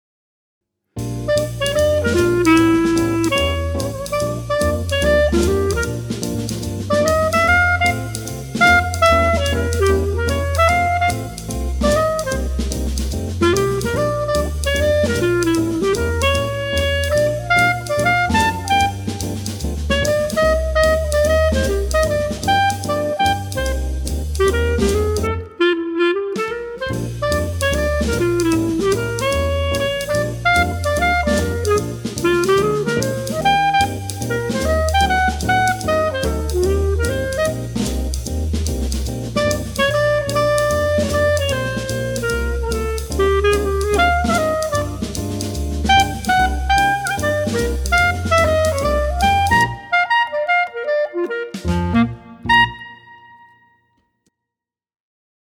46 Jazz Studies for Clarinet over three graded books